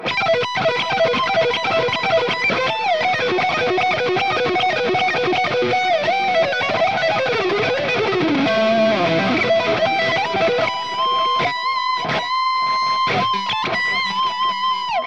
With some increadable lead tones and dark heavy rhythm tones.
Lead
RAW AUDIO CLIPS ONLY, NO POST-PROCESSING EFFECTS
Hi-Gain